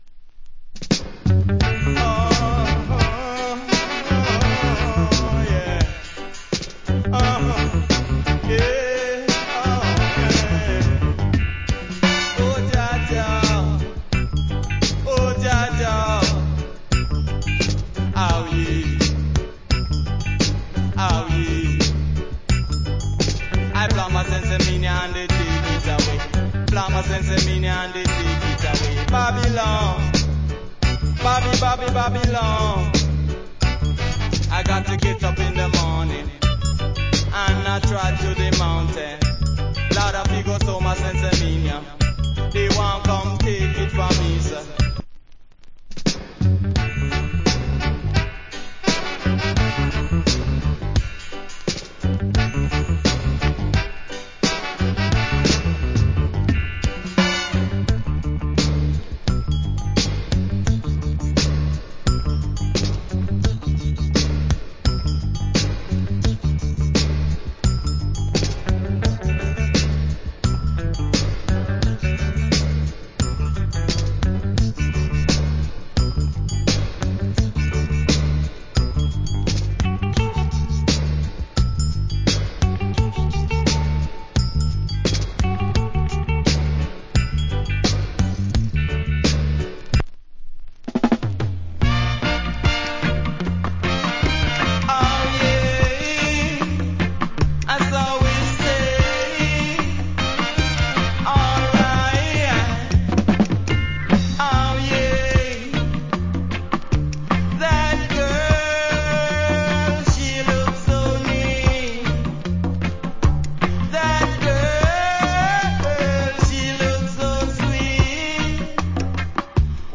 80's. Good Roots Rock Vocal.